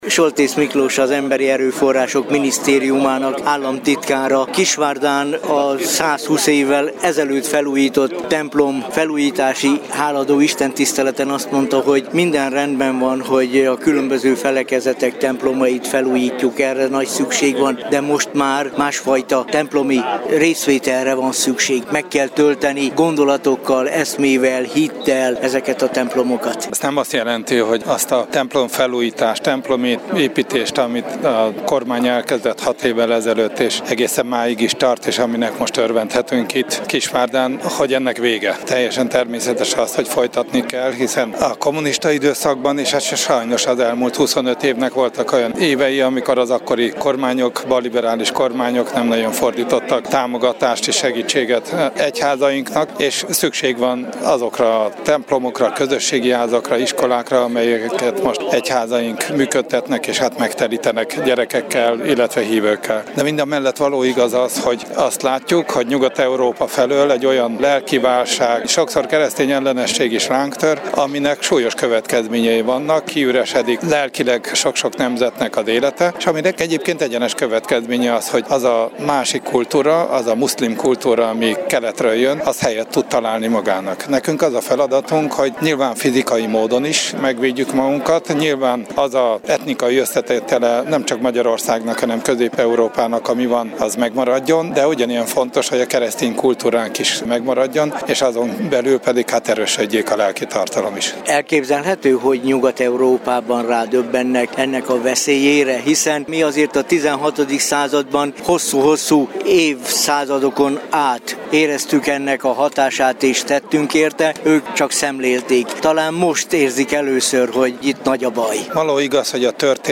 Hálaadó istentisztelet Kisvárdán - hanganyaggal
A hálaadó istentiszteleten dr. Fekete Károly, a Tiszántúli Református Egyházkerület püspöke hirdette az igét.